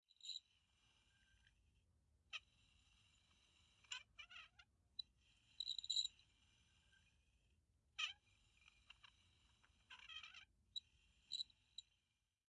大气层 风船 马达 远处的声音
描述：在汉堡港口乘船游览的Atmo。主要是风声，但您也可以听到船舶电机和一些声音。在带有x / y头的Zoom H5上录制。
Tag: 风能 船舶 风暴 ATMO 声音 船舶马达 暴风雨